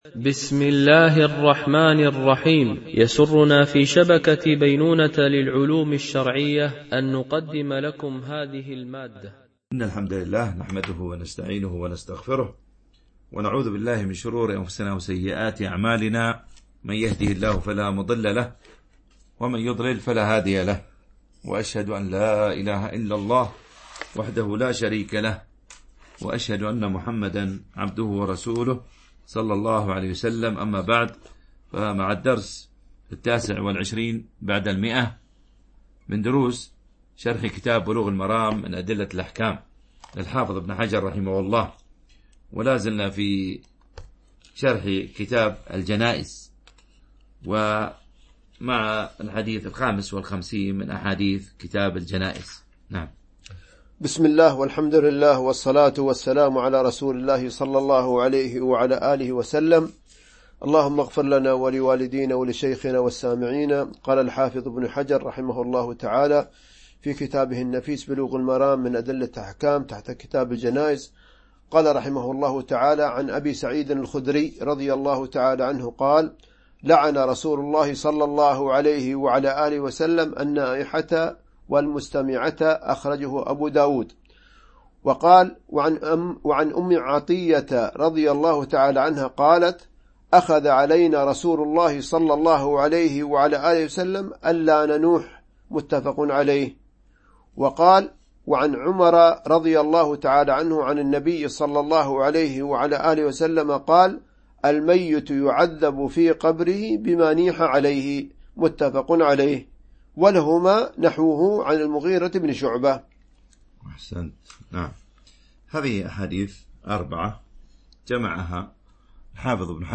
شرح بلوغ المرام من أدلة الأحكام - الدرس 129 ( كتاب الجنائز - الحديث 588-593 )